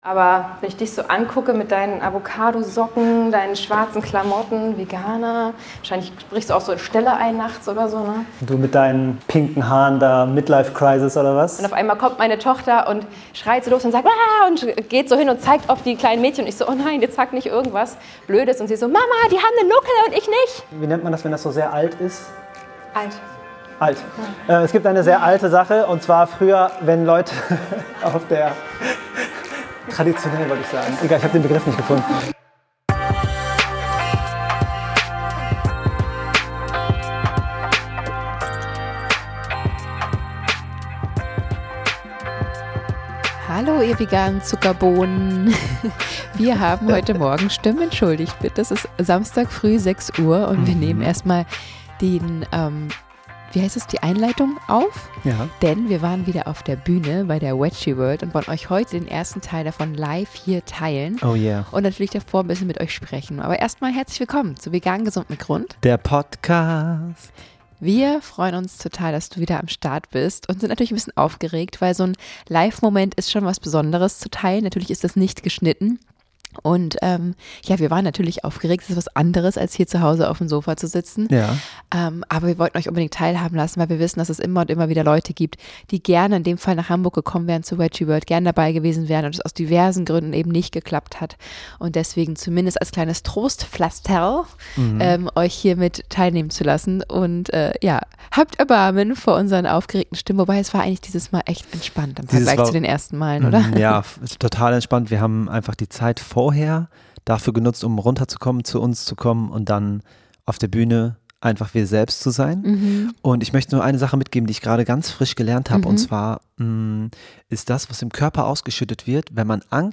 ...immer diese Vorurteile... Live Podcast bei der Veggieworld 2024 in Hamburg ~ Vegan Superheroes Podcast
In dieser Episode nehmen wir das Thema Vorurteile auseinander – und zwar live von der Bühne der Veggieworld in Hamburg! Wir sprechen darüber, wie Vorurteile entstehen, warum sie oft so hartnäckig sind und wie sie das Leben von Veganer*innen beeinflussen können.